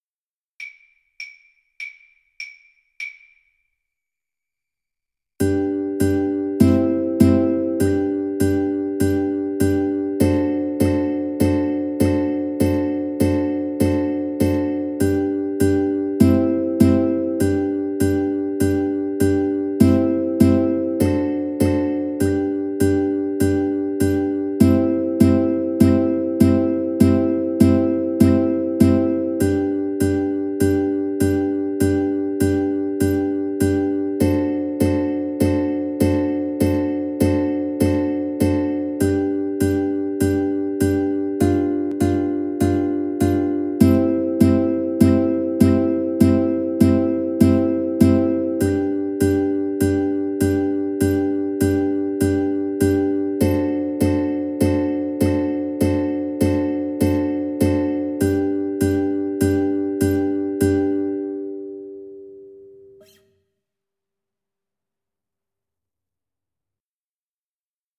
World Trad. Aloha Oe (Farewell to Thee) (Hawaiian traditional) Ukulele duet version
Free Sheet music for Ukulele duet
G major (Sounding Pitch) (View more G major Music for Ukulele duet )
4/4 (View more 4/4 Music)
World (View more World Ukulele duet Music)